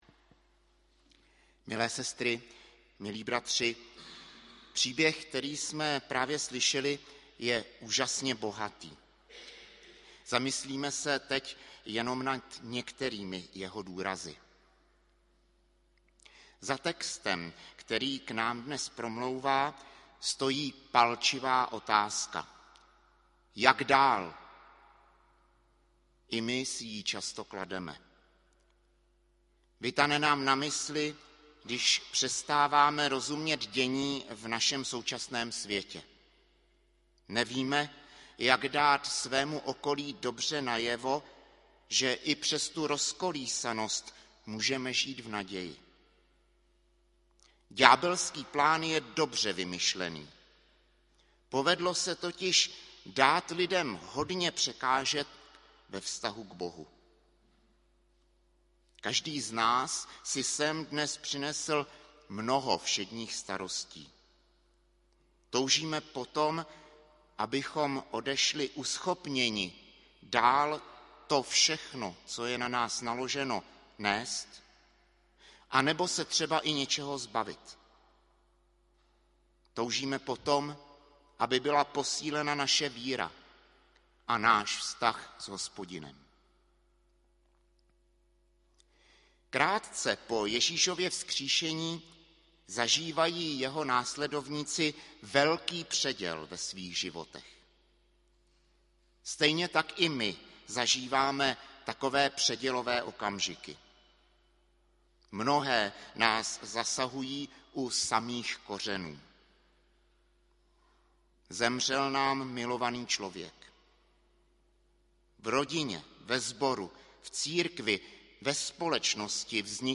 Třetí neděle po sv. Trojici 25. června 2023
audio kázání